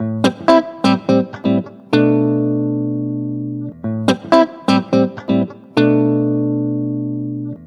Electric Guitar 08.wav